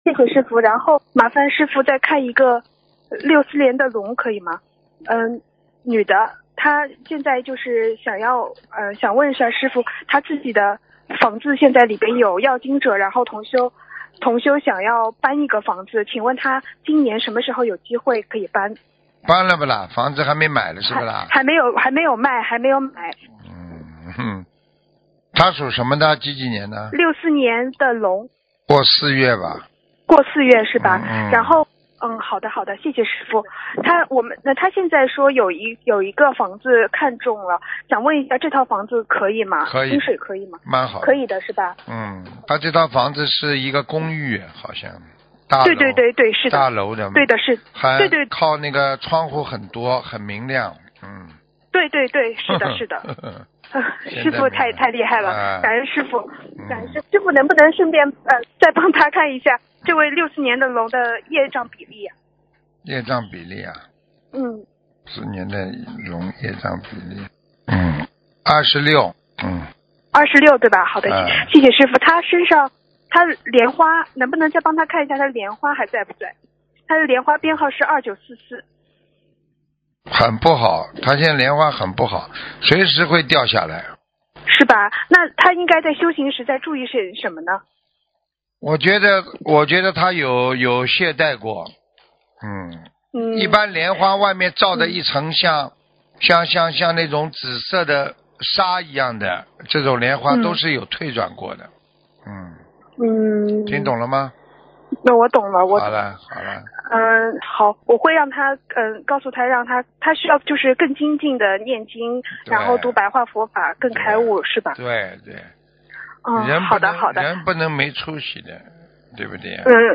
女听众:师父，您好。